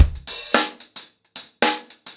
DaveAndMe-110BPM.29.wav